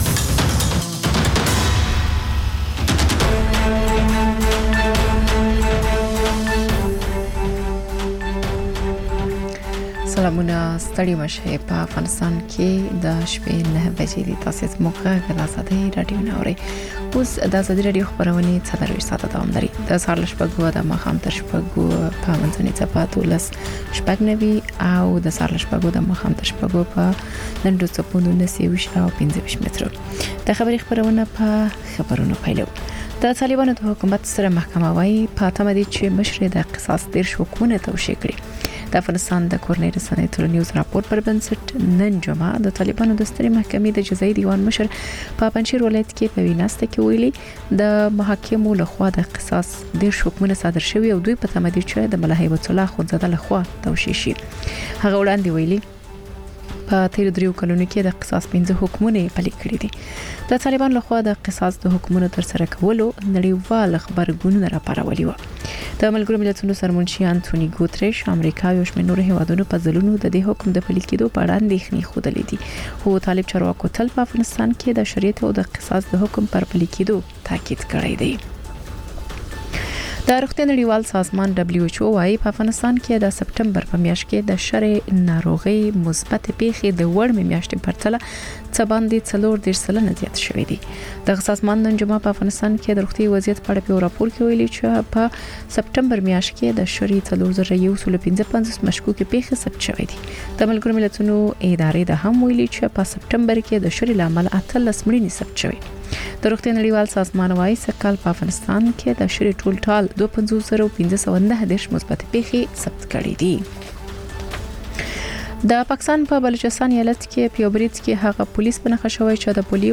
ماخوستنی خبري ساعت